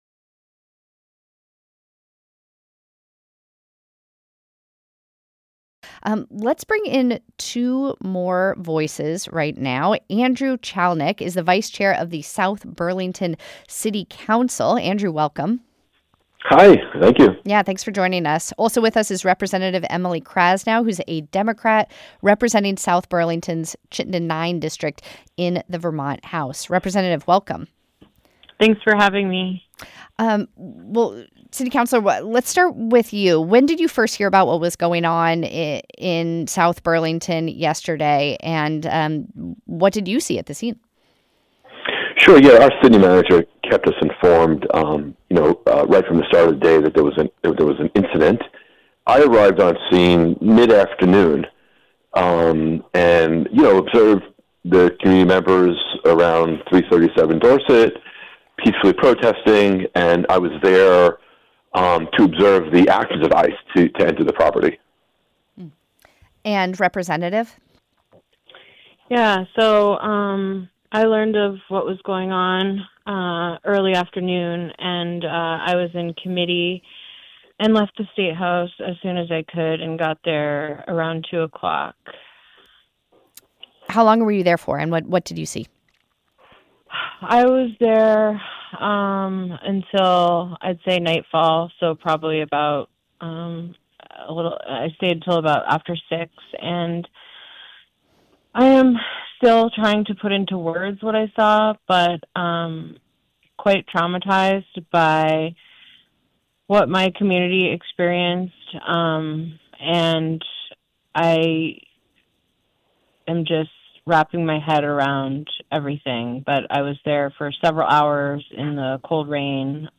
My comments on Vermont Edition on the ICE Raid that took place on Dorset Street on March 11, 2026.